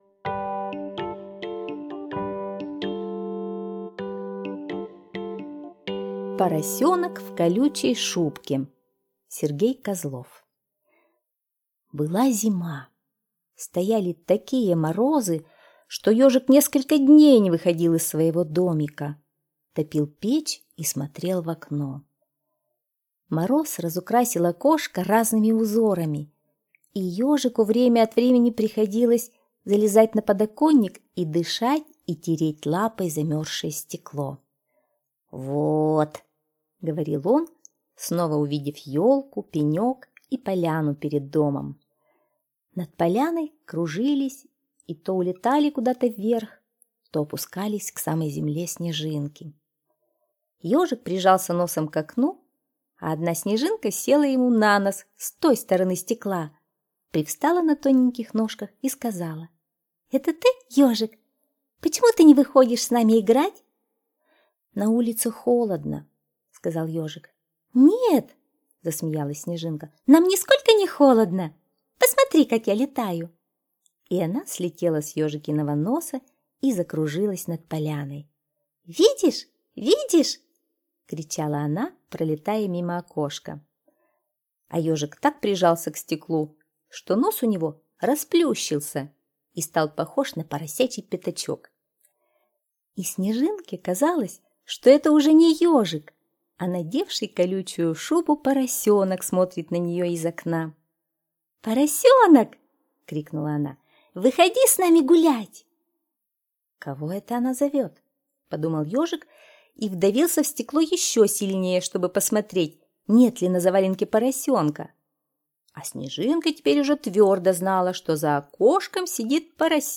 Слушайте Поросенок в колючей шубке - аудиосказка Козлова С.Г. Сказка про Ежика, который в холодный зимний день разговаривал со снежинкой за окном.